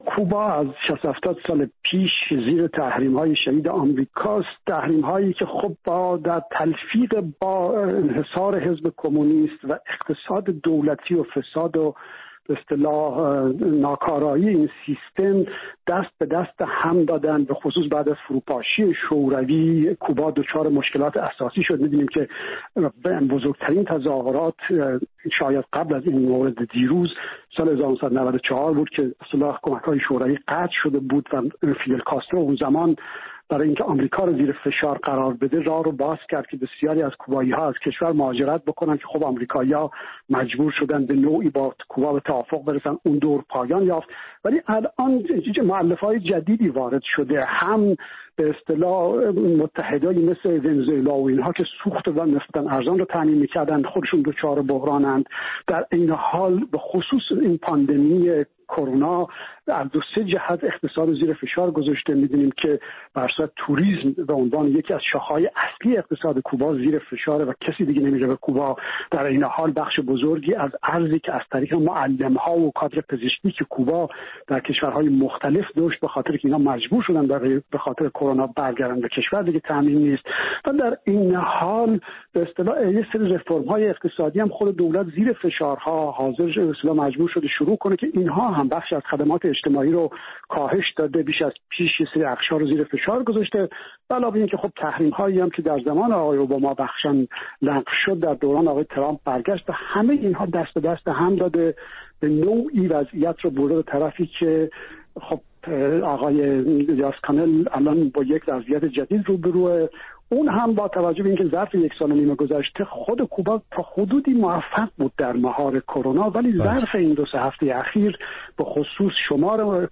گفت و گویی